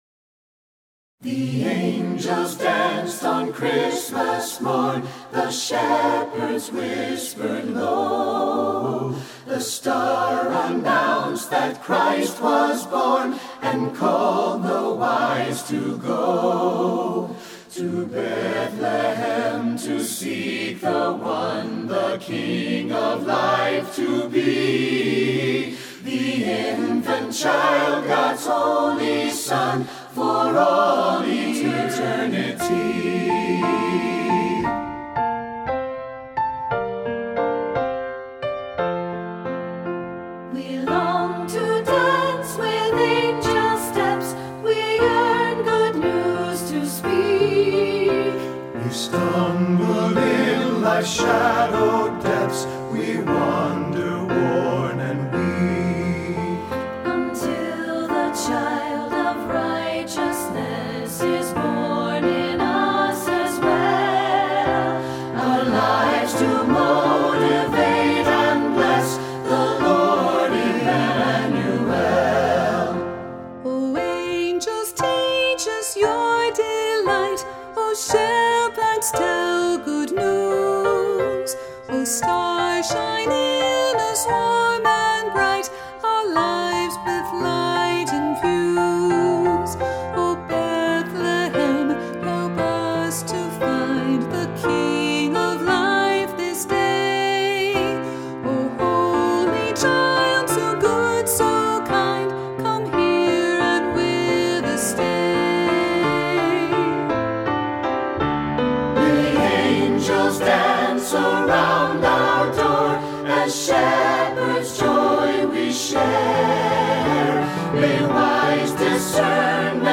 Voicing: SATB,Soloist or Soloists